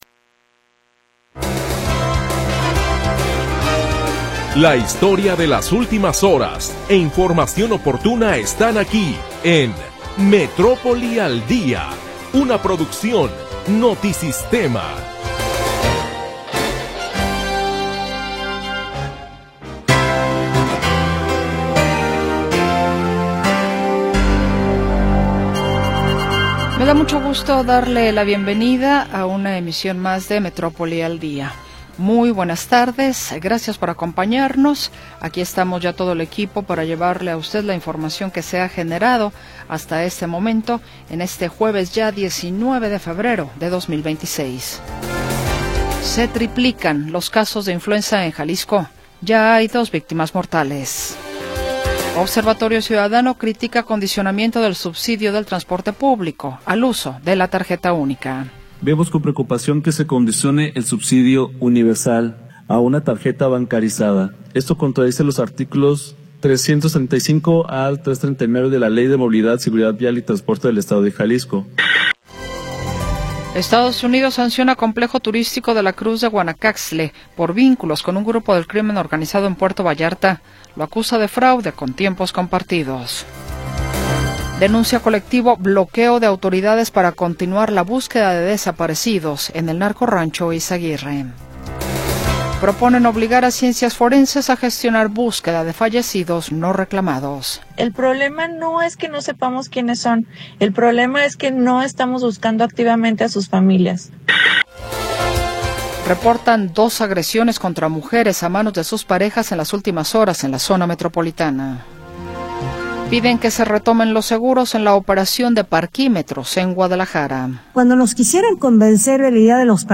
Primera hora del programa transmitido el 19 de Febrero de 2026.